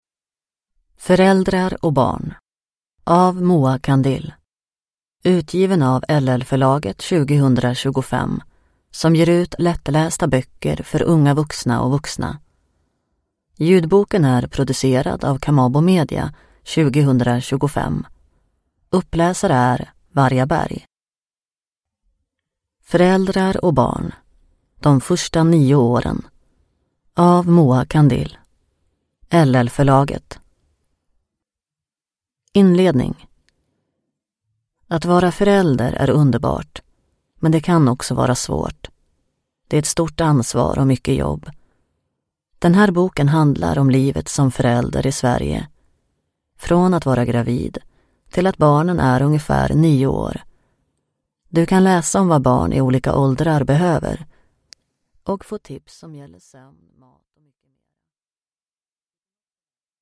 Föräldrar och barn - De första nio åren (lättläst) / Ljudbok